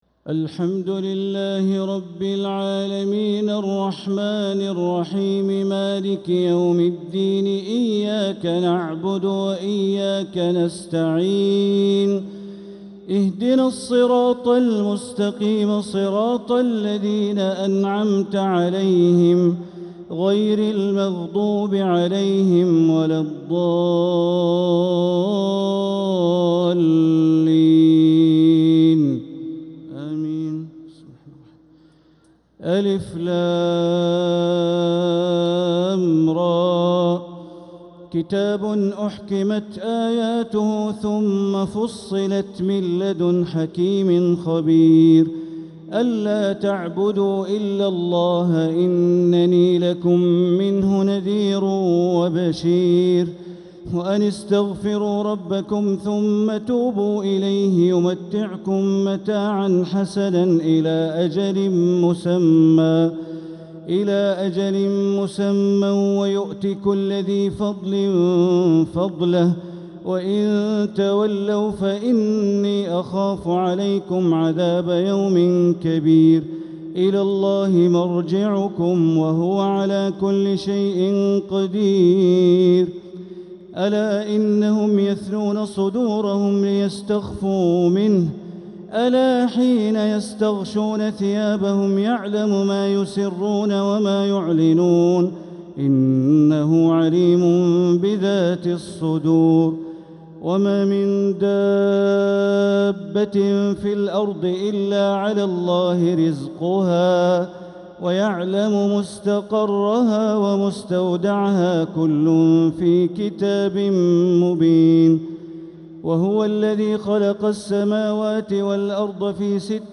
Taraweeh 15th Ramadan 1446H Surat Hud > Taraweeh Ramadan 1446H > Taraweeh - Bandar Baleela Recitations